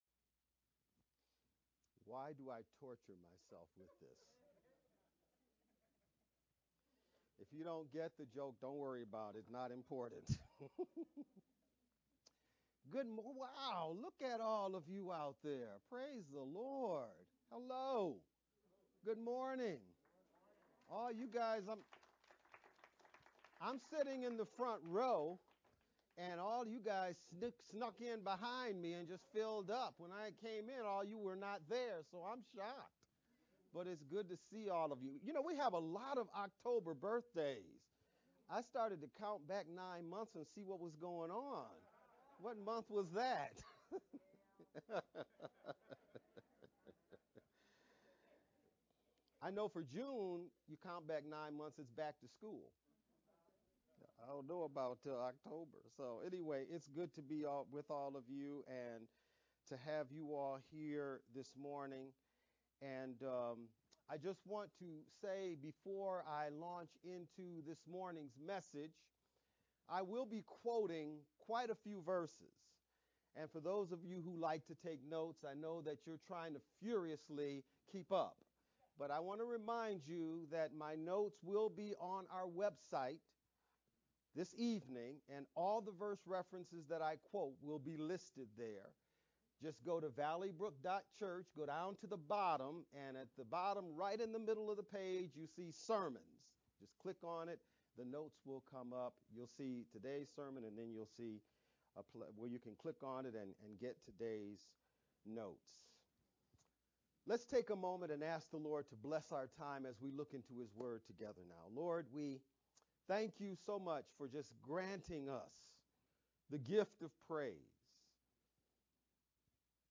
VBCC-Sermon-edited-10-2-sermon-only-CD.mp3